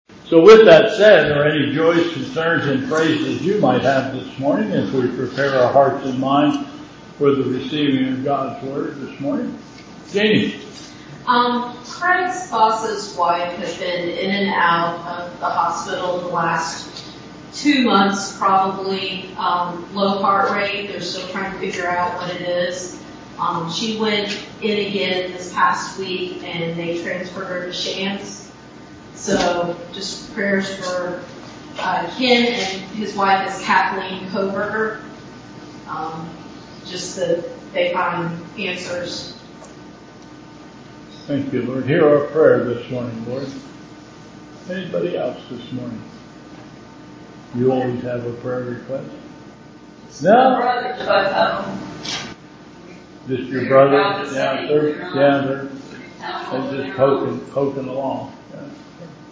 Bethel Church Service
Prayer Concerns (00:59)